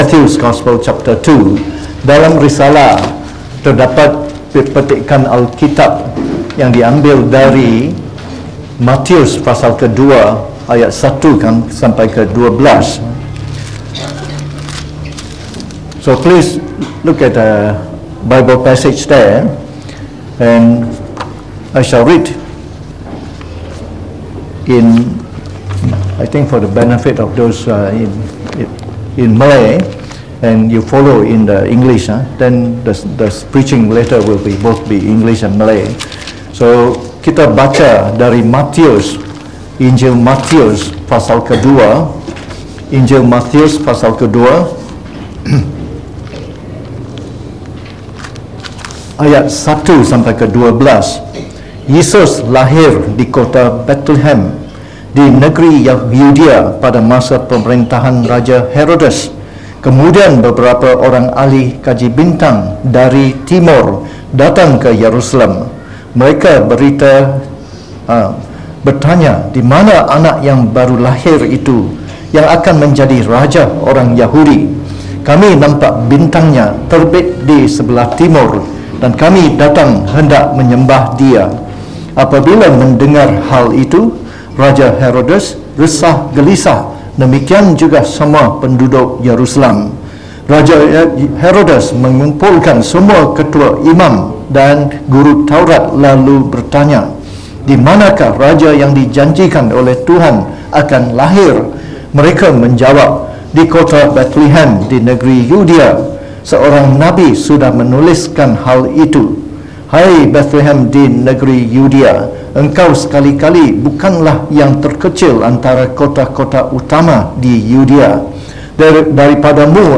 Preached on the 25th of Dec 2019 Christmas Morning